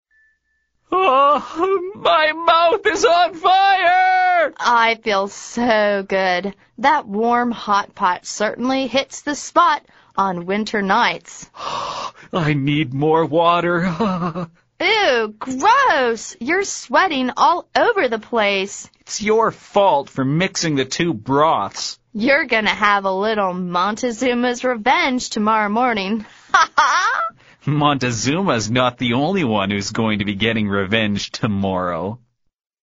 美语会话实录第108期(MP3 文本):Revenge